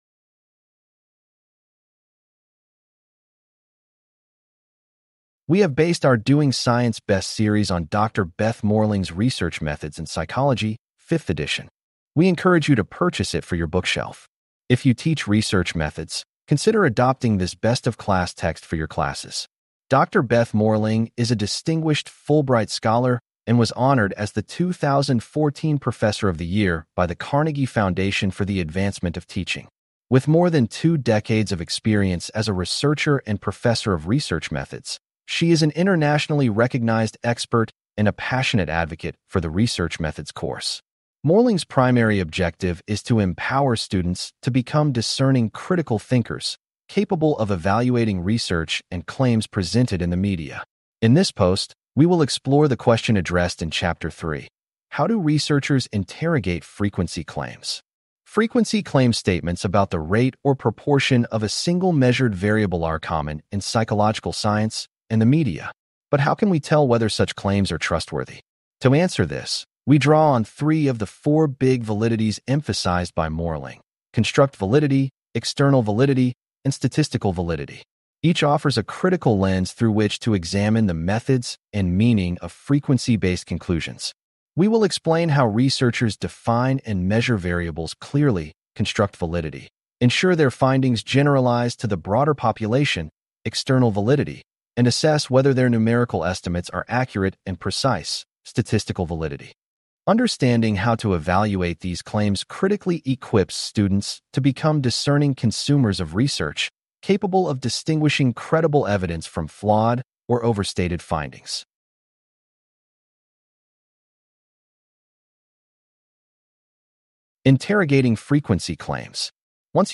CLICK TO HEAR THIS POST NARRATED Frequency claims—statements about the rate or proportion of a single measured variable—are common in psychological science and the media.